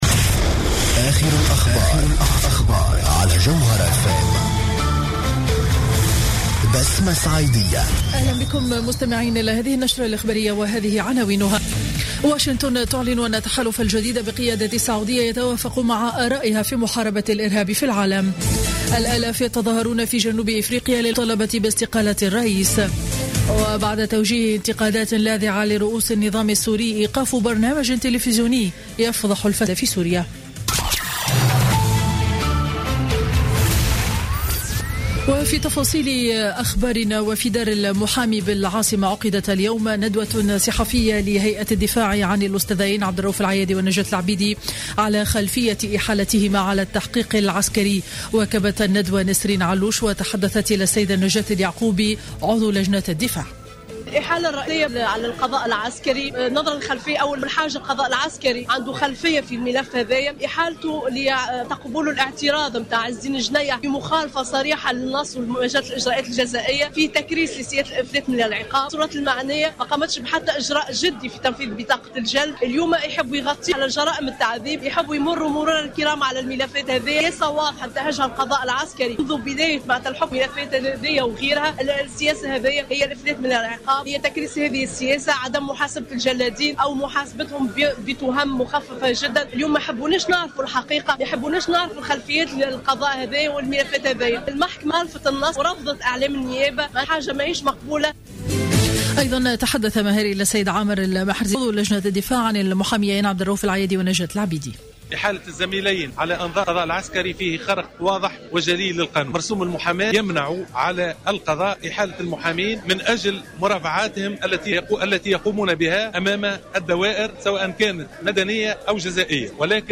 نشرة أخبار منتصف النهار ليوم الأربعاء 16 ديسمبر 2015